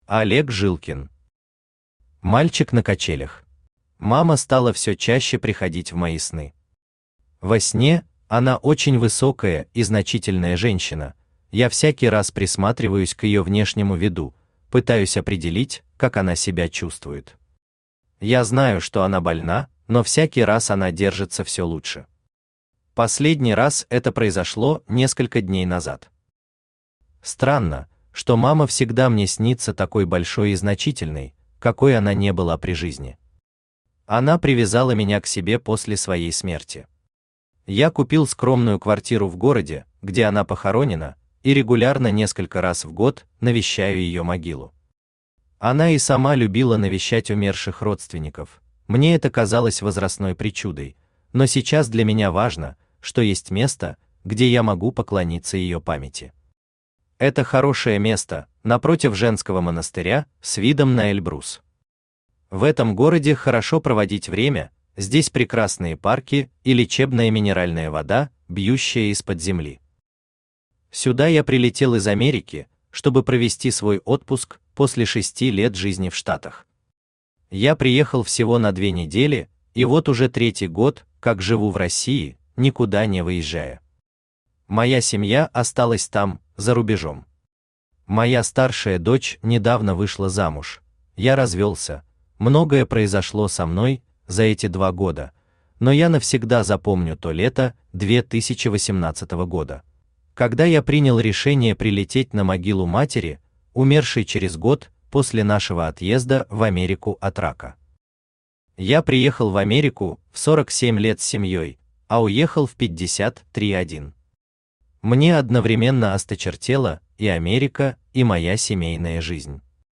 Аудиокнига Мальчик на качелях | Библиотека аудиокниг
Aудиокнига Мальчик на качелях Автор Олег Николаевич Жилкин Читает аудиокнигу Авточтец ЛитРес.